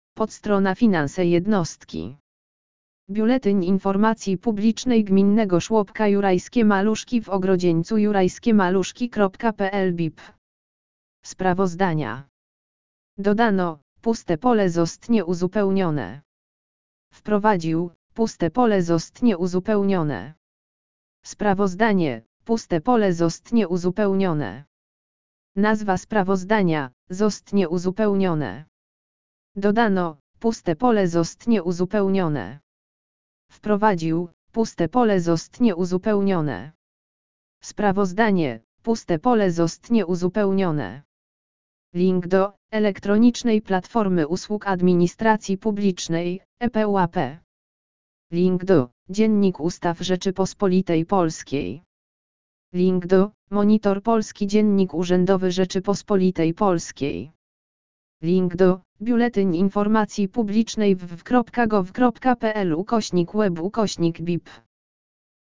lektor-BIP-finanse-jednostki-kn67nvtu.mp3